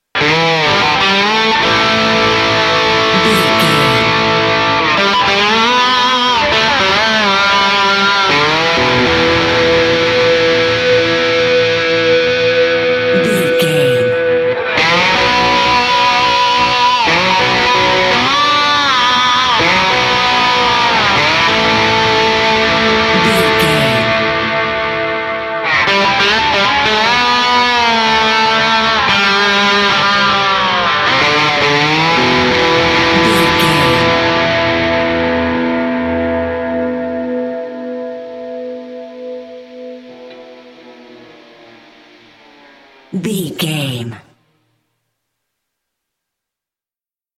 Epic / Action
Ionian/Major
electric guitar
Slide Guitar